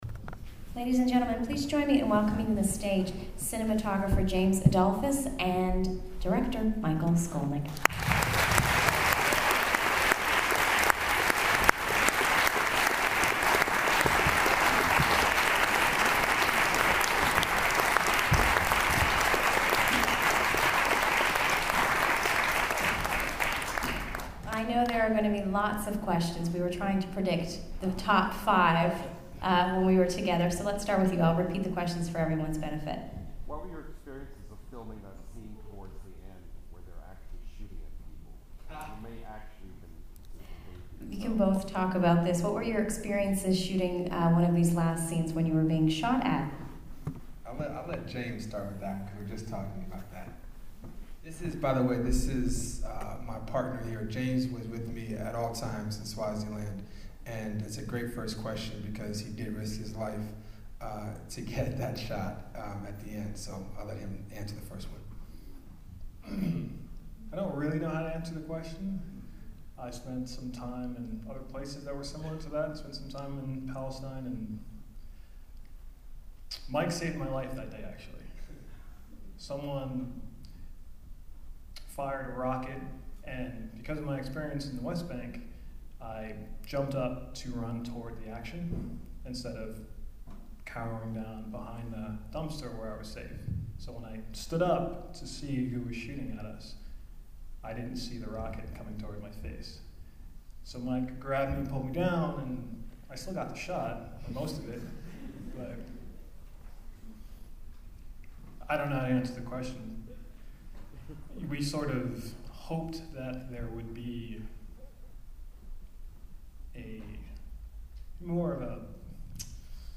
withouttheking_qa.mp3